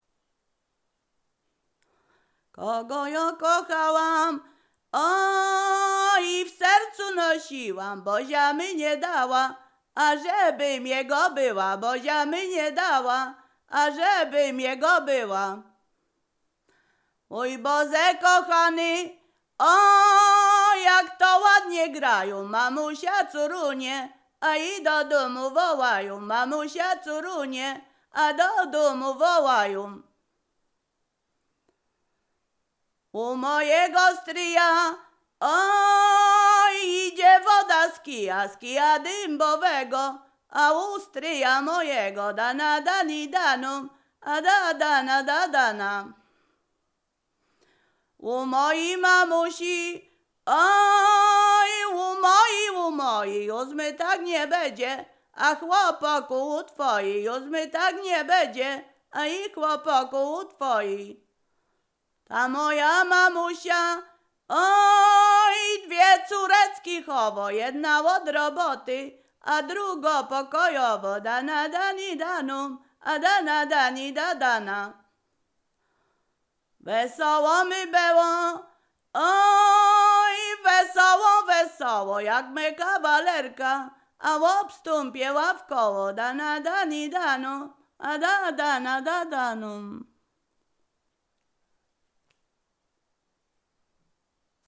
Przyśpiewki
miłosne weselne wesele przyśpiewki